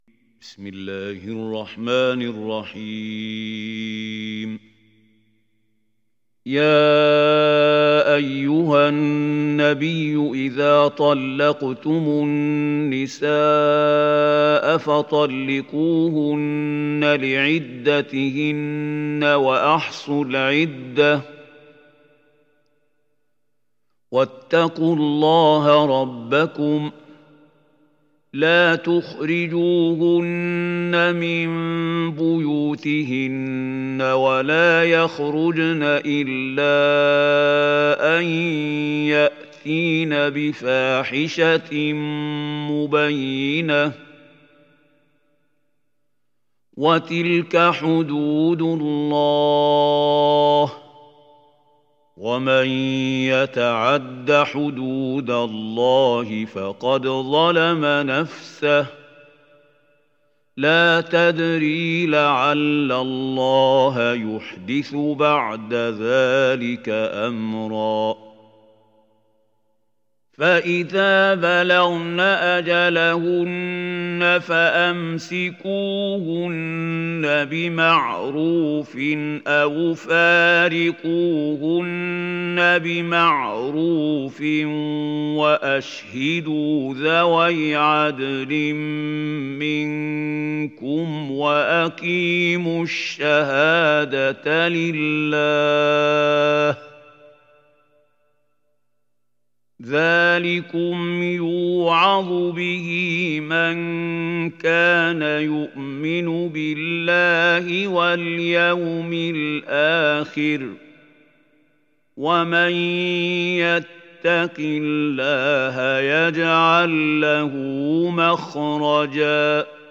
تحميل سورة الطلاق mp3 بصوت محمود خليل الحصري برواية حفص عن عاصم, تحميل استماع القرآن الكريم على الجوال mp3 كاملا بروابط مباشرة وسريعة